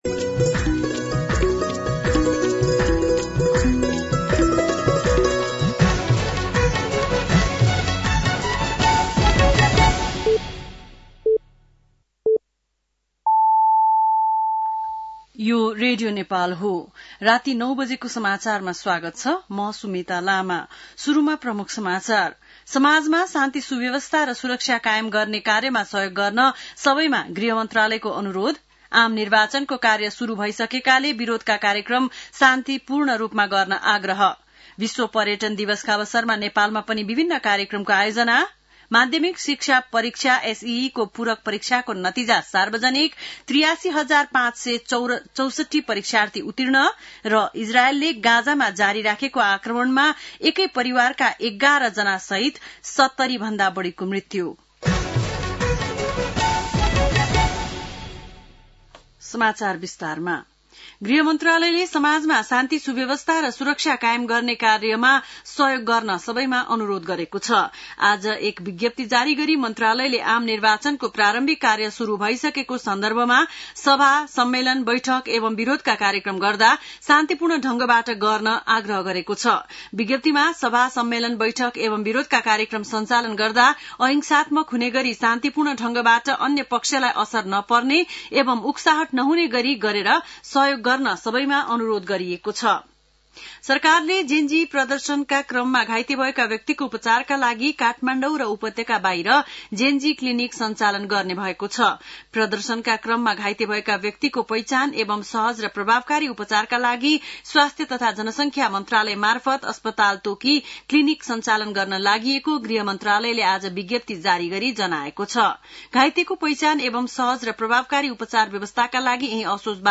बेलुकी ९ बजेको नेपाली समाचार : ११ असोज , २०८२
9-pm-nepali-news-1-3.mp3